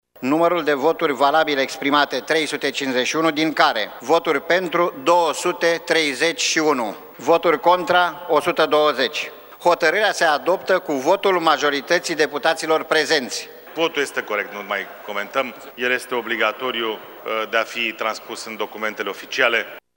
Anunțul a fost făcut de secretarul Camerei Deputaților, Florin Pâslaru: